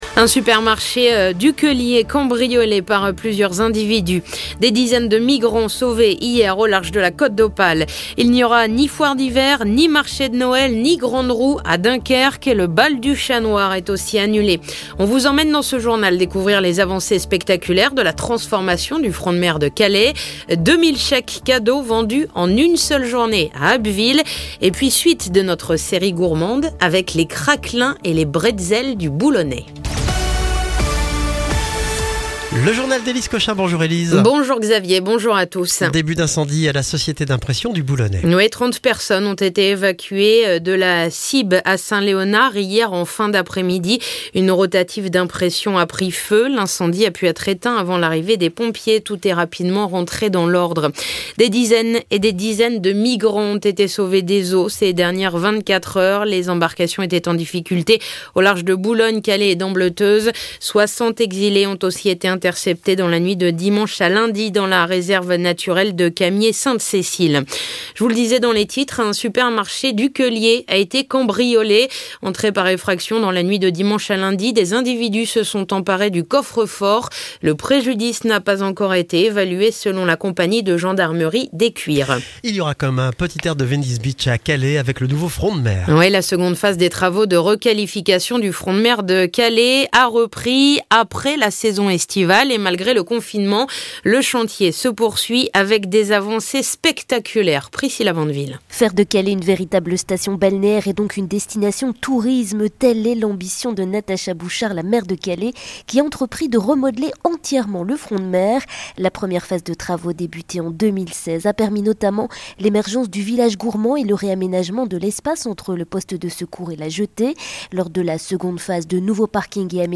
Le journal du mardi 1er décembre